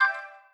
UI_Update.wav